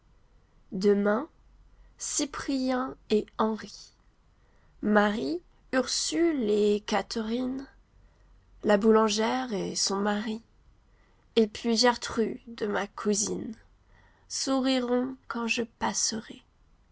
female_11743.wav